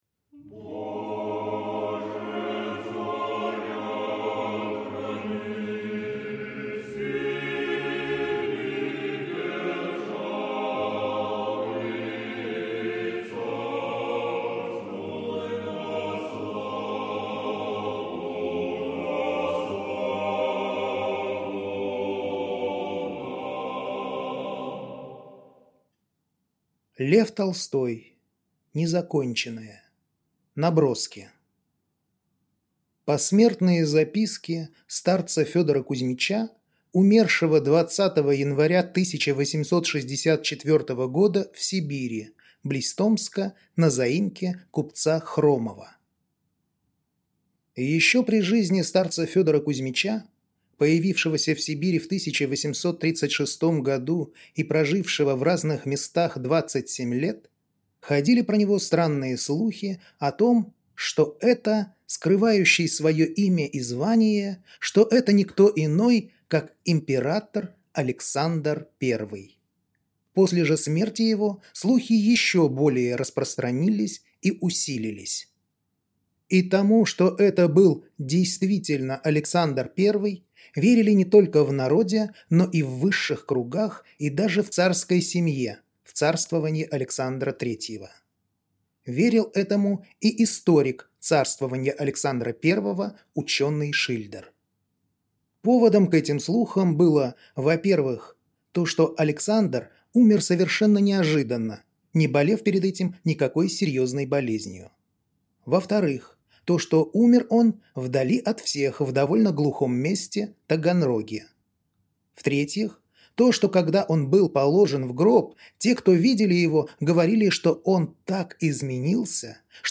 Аудиокнига Незаконченное. Наброски | Библиотека аудиокниг